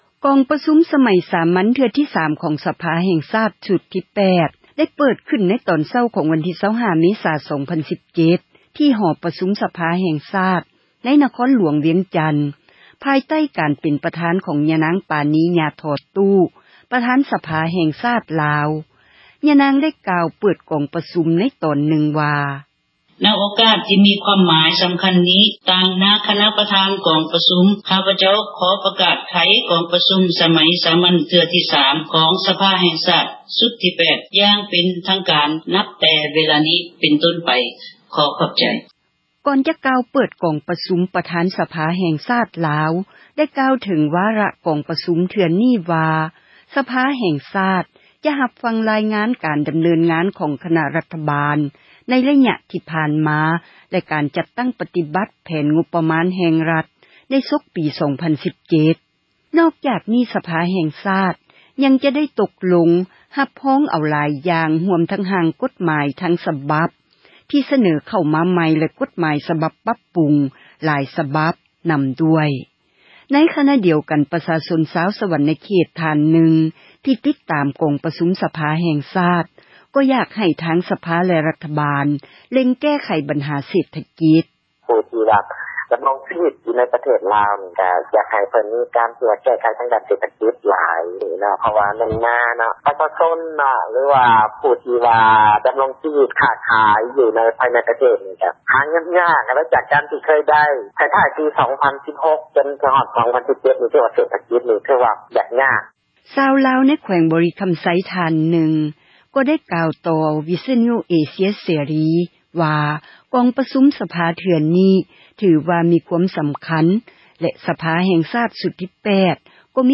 ດັ່ງຍານາງ ໄດ້ກ່າວເປີດ ກອງປະຊຸມ ໃນຕອນນຶ່ງວ່າ:
ຊາວລາວໃນແຂວງບໍຣິຄຳໄຊ ທ່ານນຶ່ງກໍກ່າວຕໍ່ ວິທຍຸເອເຊັຽເສຣີ ວ່າກອງປະຊຸມ ສະພາເທື່ອນີ້ ຖືວ່າມີ ຄວາມສຳຄັນ ແລະສະພາແຫ່ງຊາຕ ຊຸດທີ 8 ກໍມີຜົລງານ ທີ່ພົ້ນເດັ່ນ ຈໍານວນນຶ່ງ ໃນການແກ້ໄຂ ບັນຫາ ຂອງປະຊາຊົນ: